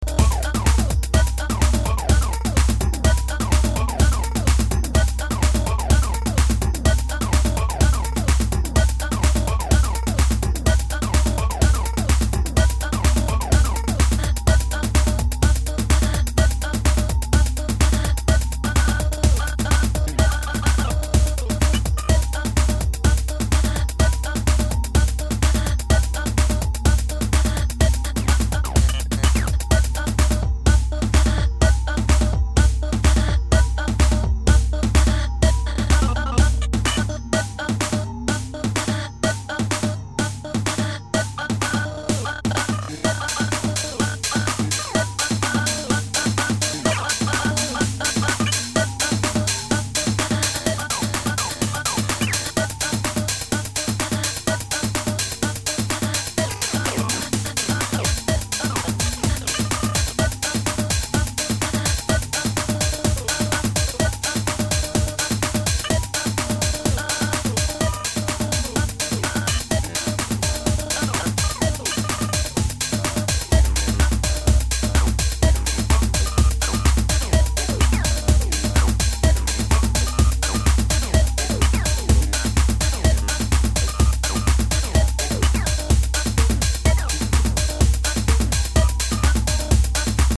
Five badass house jams.
House